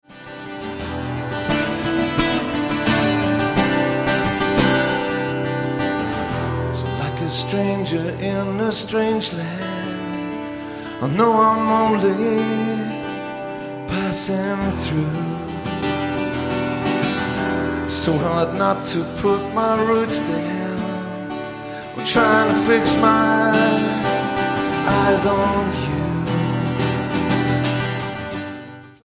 STYLE: Pop
minimal percussion and strings